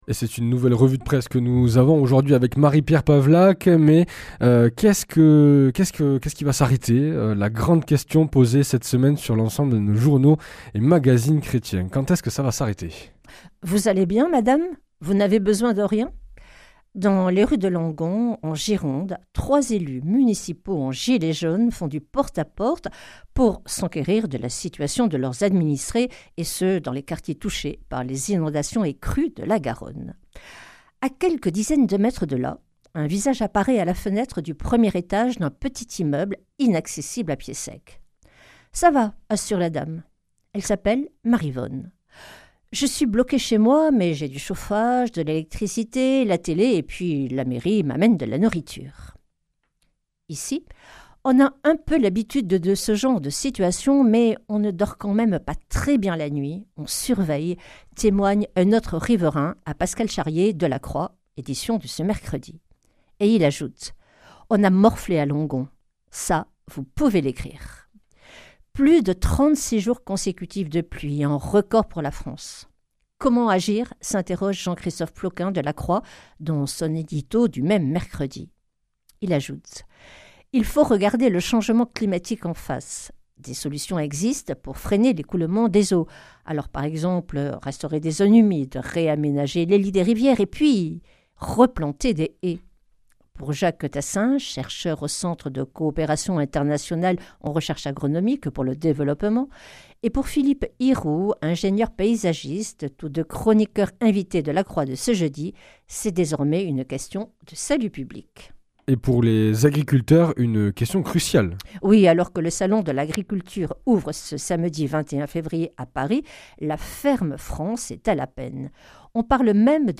Revue de presse
Une émission présentée par